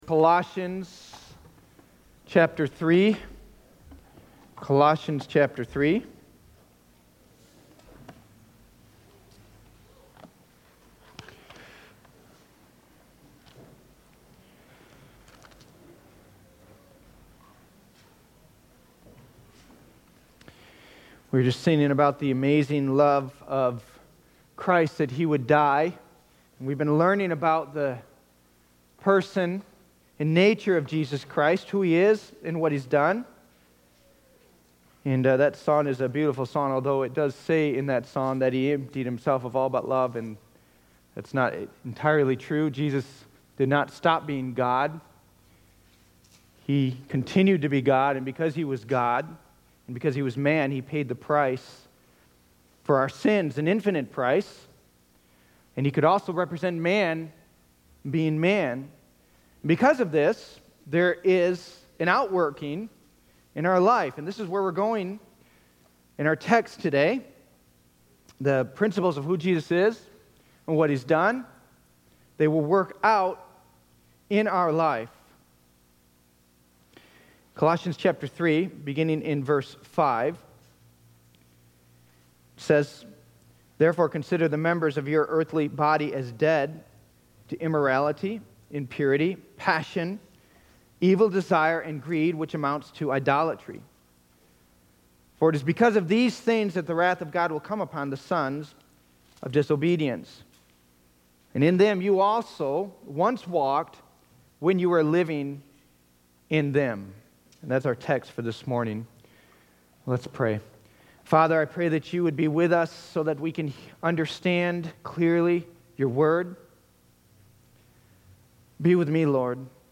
Sermon Links Listen/Download audio